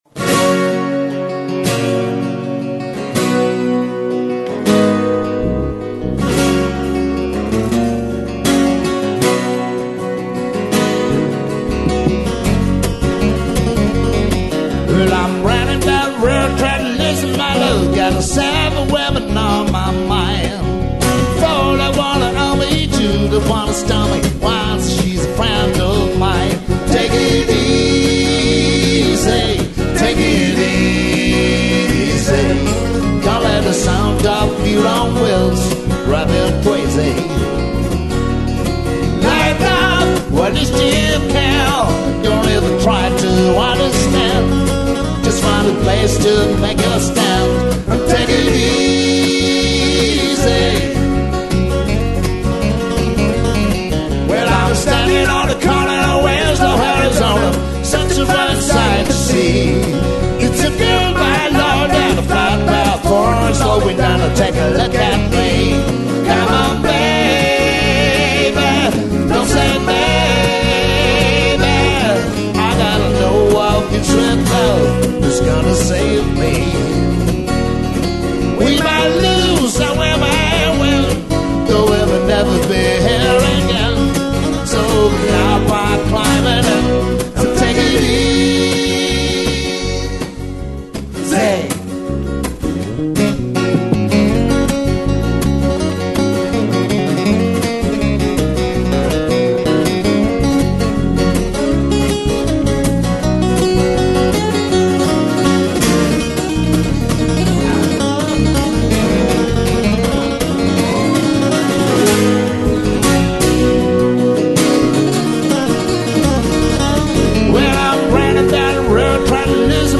chitarra e voce
basso e voce.